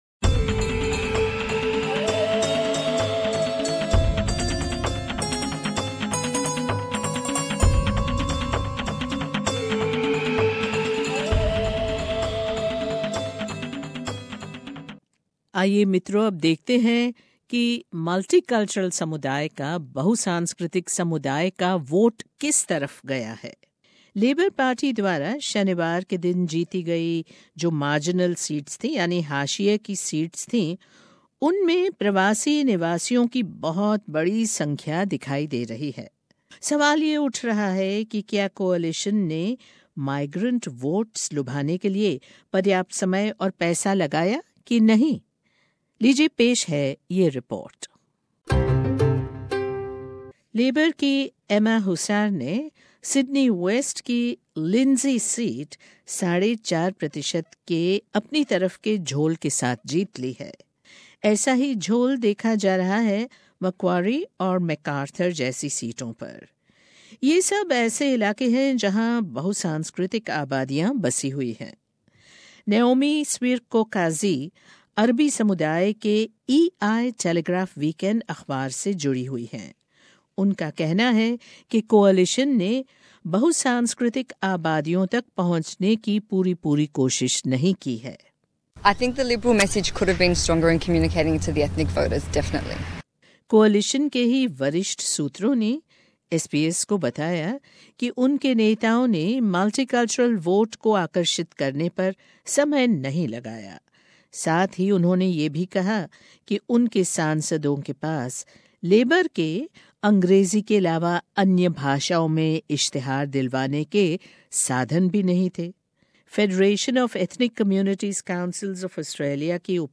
लेबर पार्टी द्वारा शनिवार को कुछ हाशिये की सेटों पर जीत पाने से ये दिखाई दे रहा है कि उन निर्वाचन क्षेत्रो में प्रवासियों की बहुत बड़ी संख्या है। अब सवाल ये उठ रहा है कि क्या कोअलिशन ने प्रवासियों के वोट पाने के लिए पर्याप्त पैसा और समय लगाया कि नहीं?आइए जानते हैं इस रिपोर्ट से।